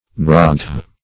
Nuraghe \Nu*ra"ghe\, n.; It. pl. -ghi. Also Nuragh \Nu"ragh\,
nuraghe.mp3